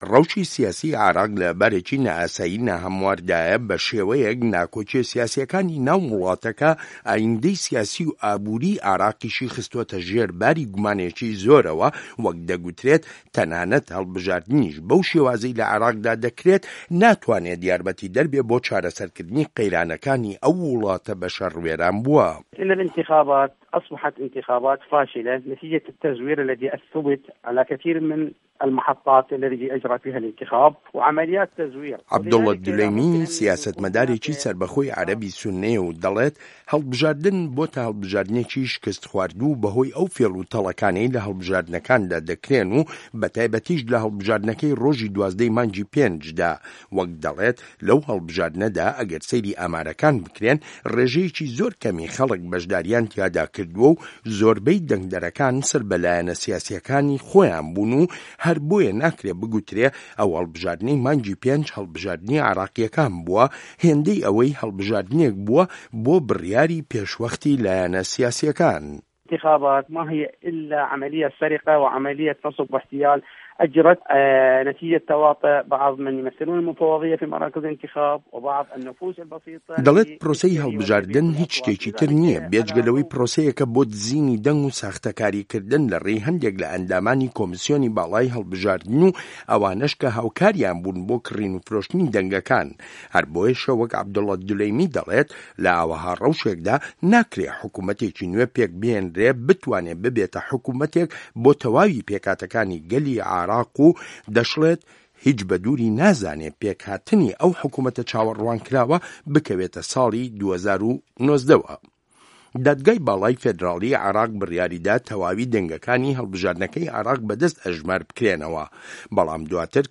ڕاپۆرت